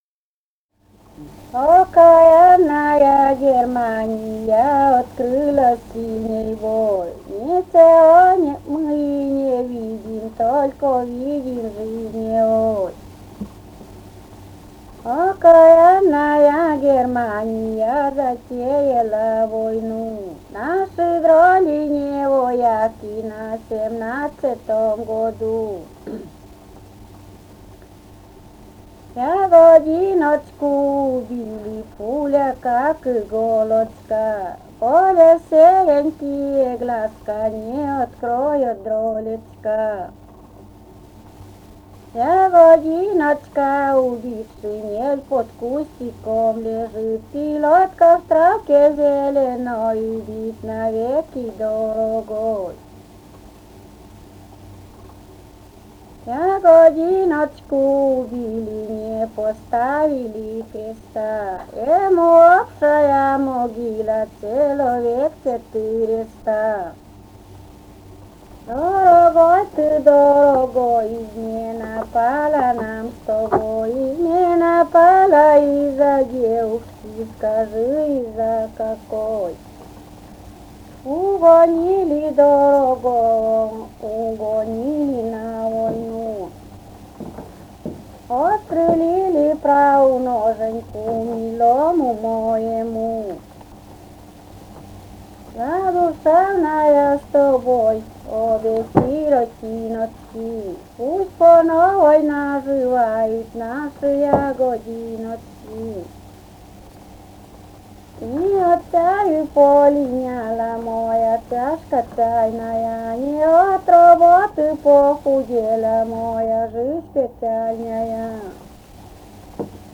Живые голоса прошлого 039. «Окаянная Германия» (частушки).